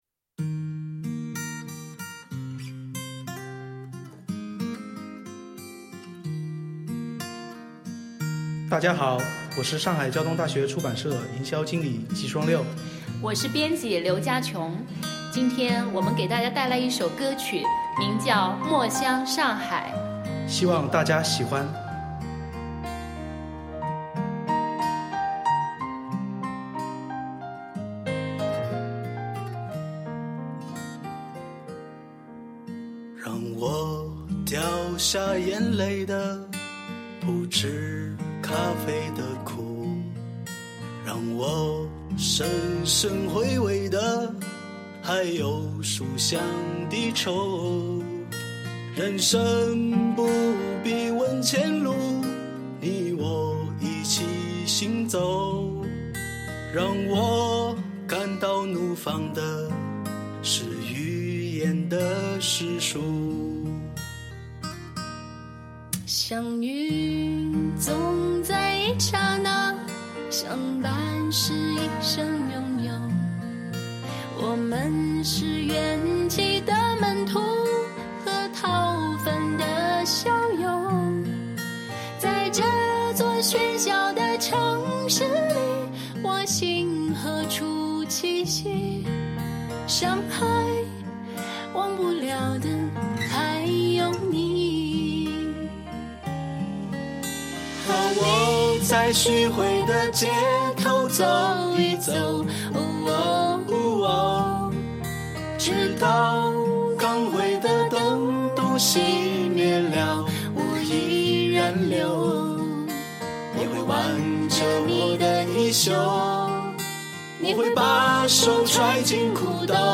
悠悠唱出无尽的诗意和情怀。
▌书店主题曲 ▌